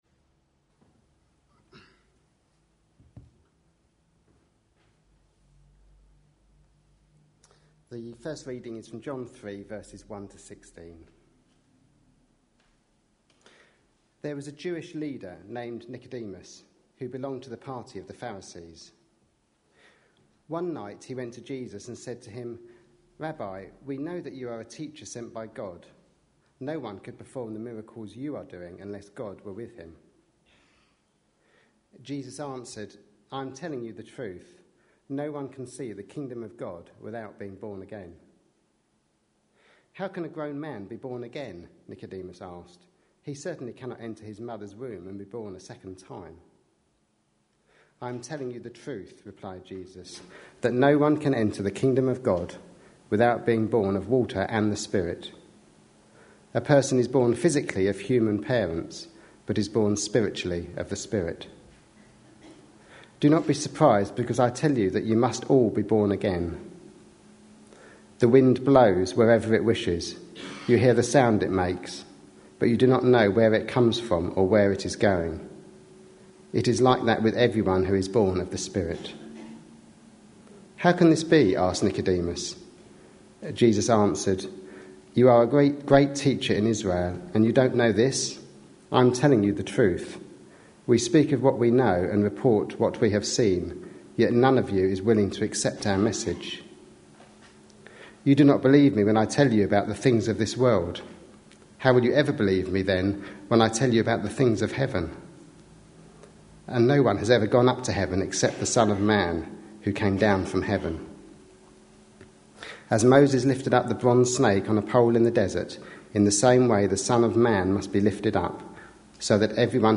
A sermon preached on 17th February, 2013, as part of our Passion Profiles and Places -- Lent 2013. series.